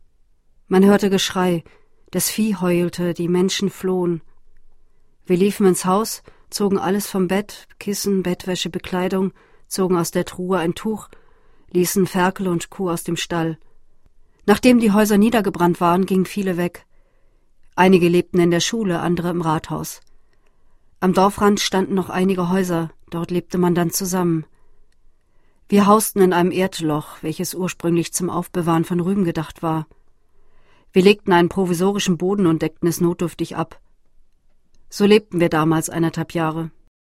Schauspielerin, Sprecherin
Sprechprobe: eLearning (Muttersprache):